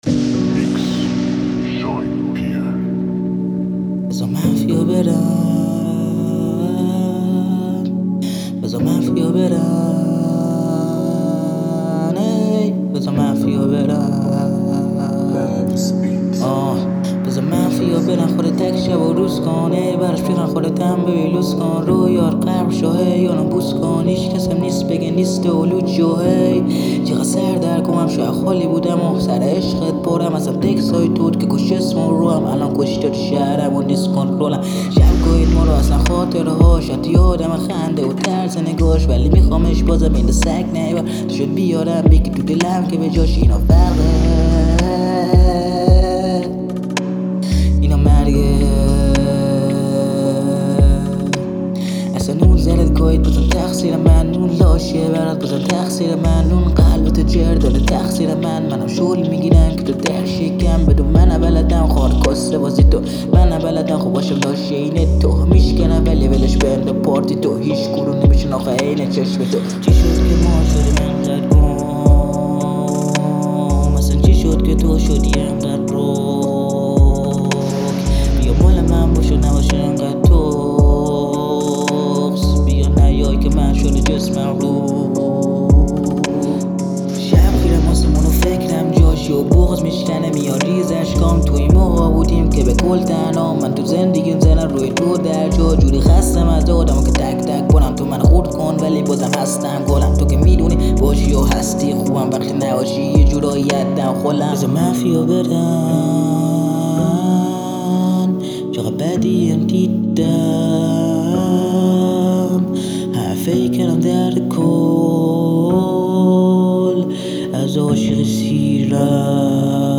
رپفارسی